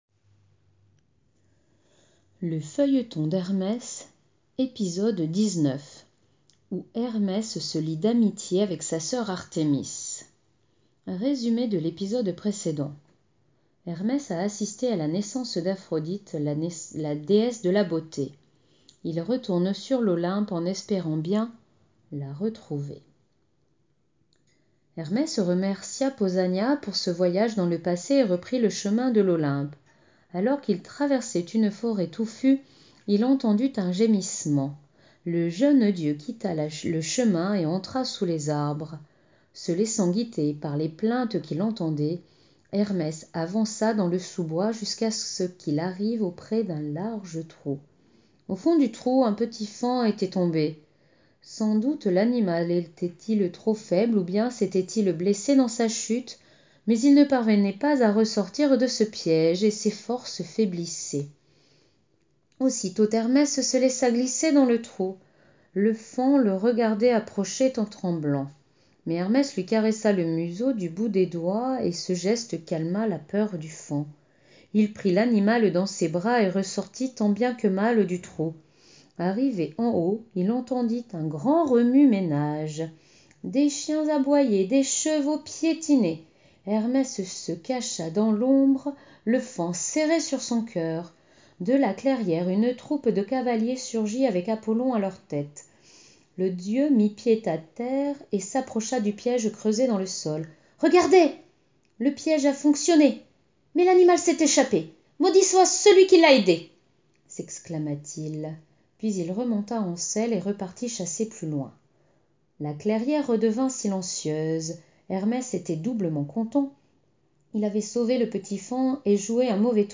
Lecture de l'épisode 19 du Feuilleton d'Hermès.